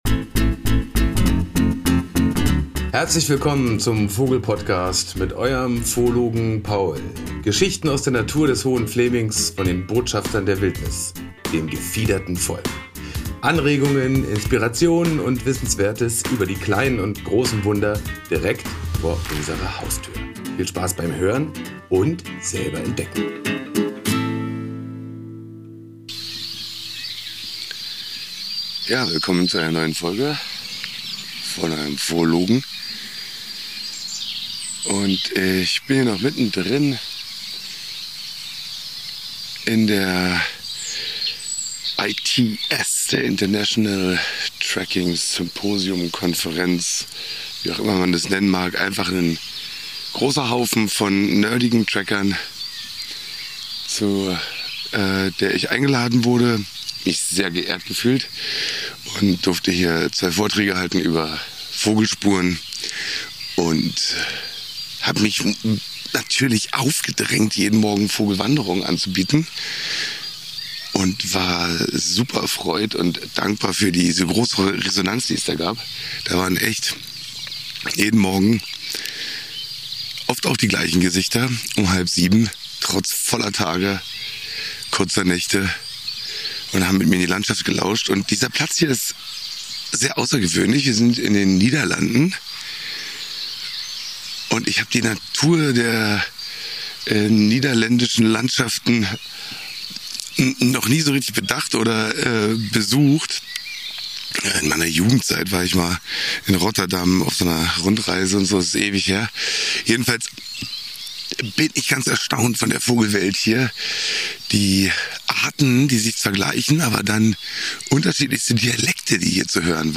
Beschreibung vor 1 Jahr (Empfehlung: Klangbildreise mit Kopfhörern genießen!)
Meine Vogelwanderungen waren dominiert von den wunderschönen Gesängen der Rotkehlchen. Die Dichte der einzelnen Individuen war überwältigend, die Stophen des einen Sängers verschmolzen mit den Motiven der benachbarten Rotkehlchen und bedeckten die Landschaft mit perlenden Meldoien.